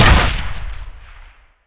fire_penetrator.wav